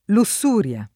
lussuria [ lu SS2 r L a ] s. f.